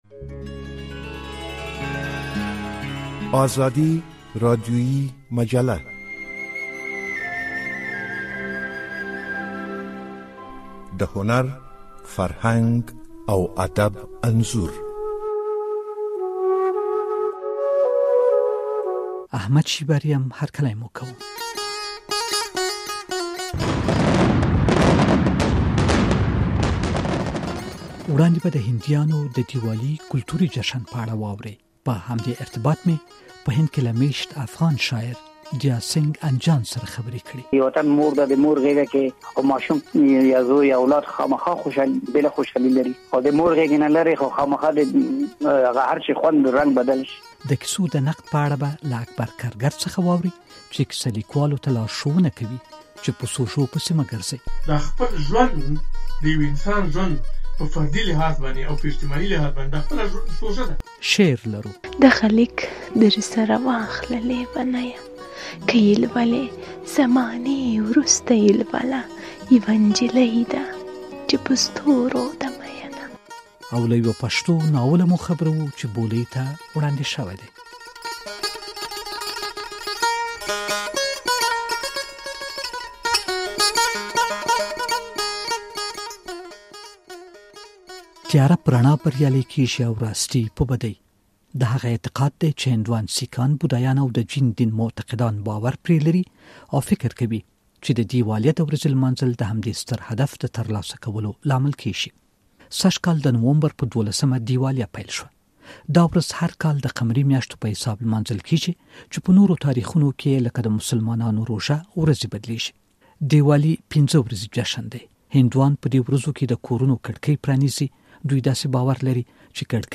هندیان د دیوالي کلتوري جشن څرنګه نمانځي. په دې خپرونه کې د کیسو نقد درته وړاندې کېږي او شعر هم اورېدلی شئ.